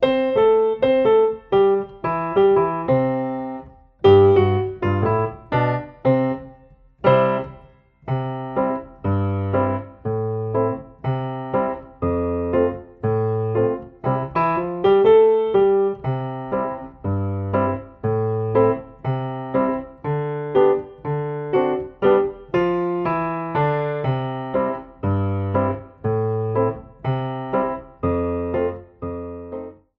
Besetzung: Violoncello